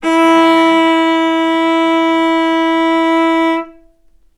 vc-E4-ff.AIF